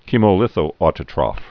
(chēmō-lĭthōtə-trŏf, -trōf, kĕmō-)